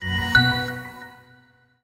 PowerOff.ogg